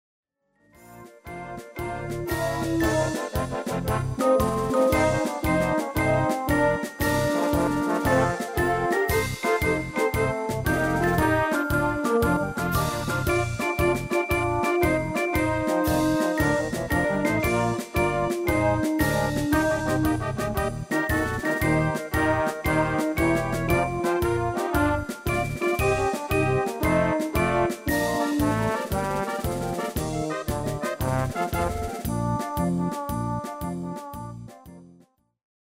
Basic MIDI File Euro 8.50
Demo's zijn eigen opnames van onze digitale arrangementen.